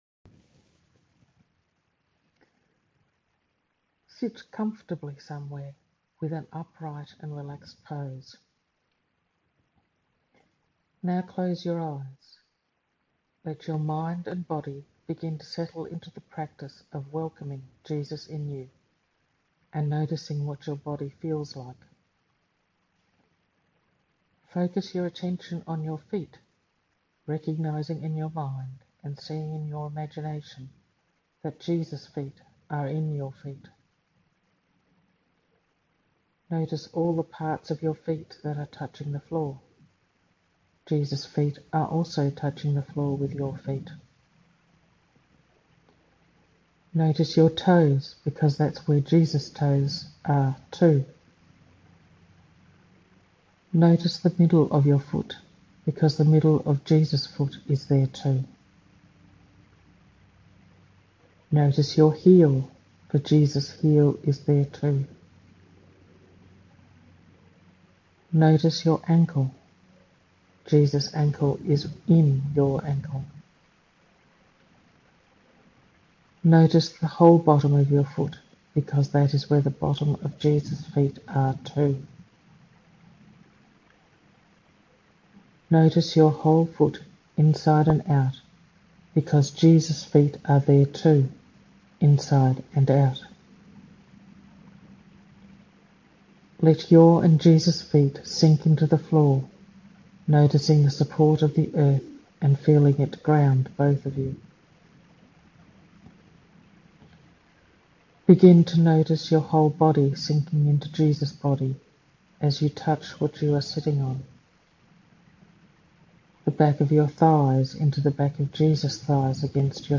A meditation to help you be aware of Christ in you:
jesus-in-you-breathing-meditation.mp3